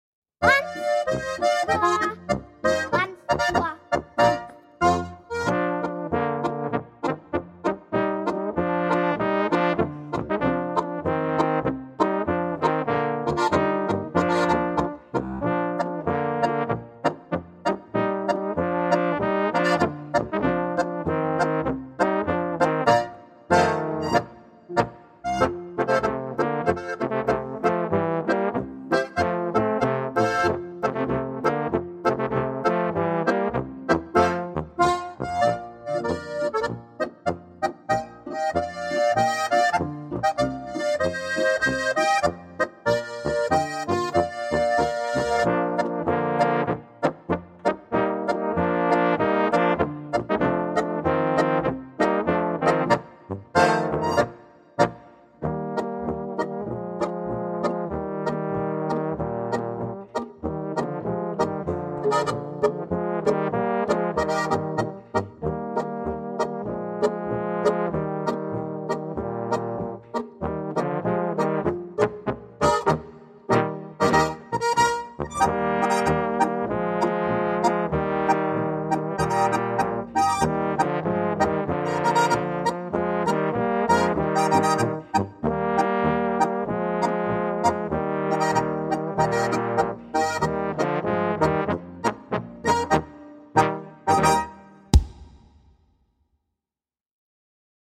Besetzung: Pos